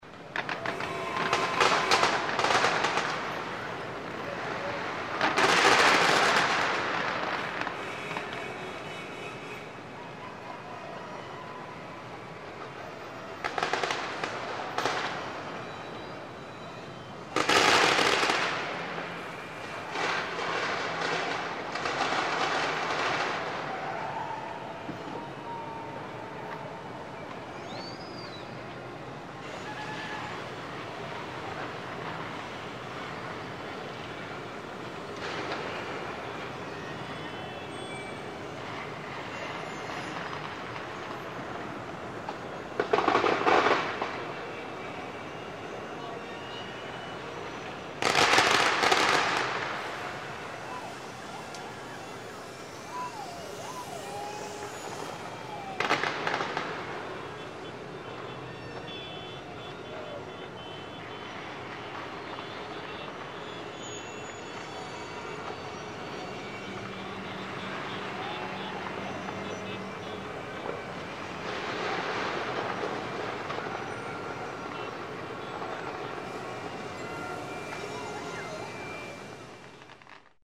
Звуки салюта
Шум улицы в новогоднюю ночь, салюты и фейерверки